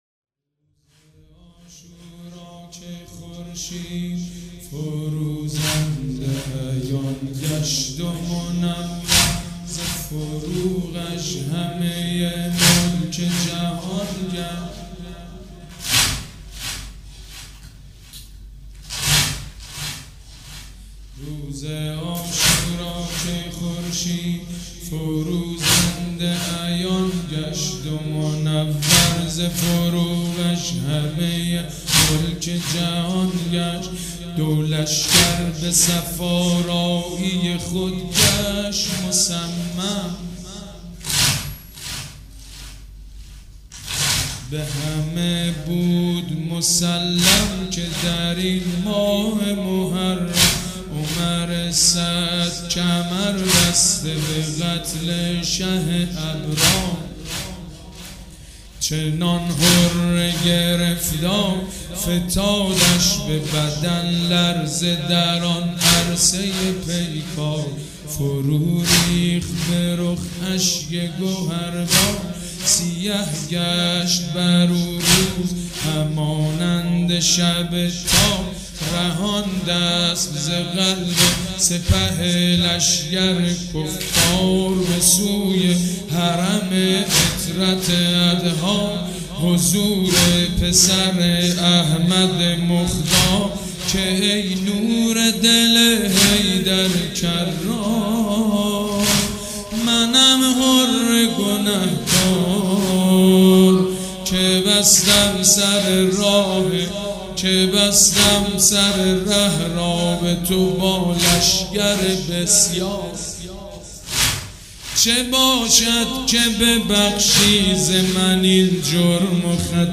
حاج سید مجید بنی فاطمه
مراسم عزاداری شب چهارم